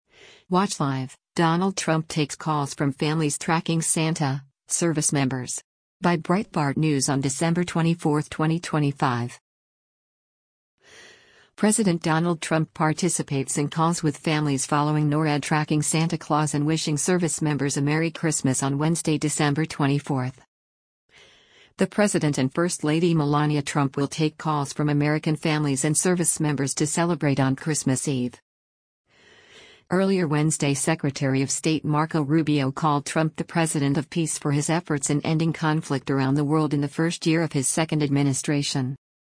President Donald Trump participates in calls with families following NORAD tracking Santa Claus and wishing servicemembers a merry Christmas on Wednesday, December 24.
The president and First Lady Melania Trump will take calls from American families and servicemembers to celebrate on Christmas Eve.